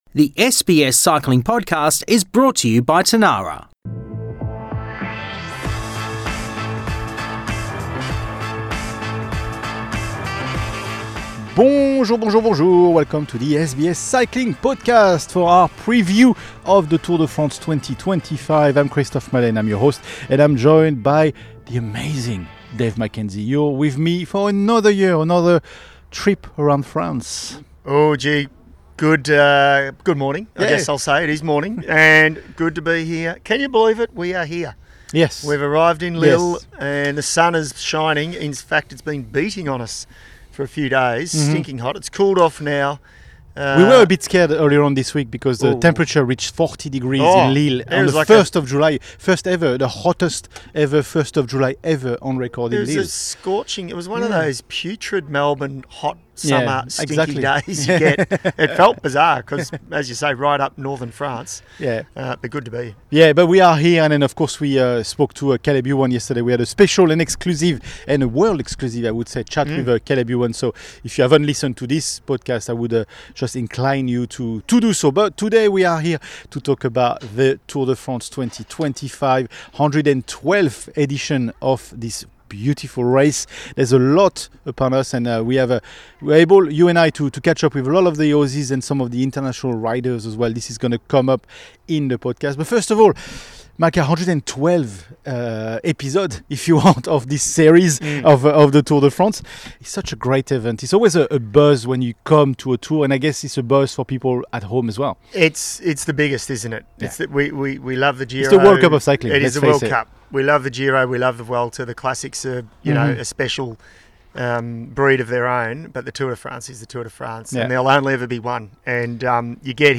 A key focus of the episode is the presence of Australian cyclists, with exclusive interviews offering insights into their preparation and mindset.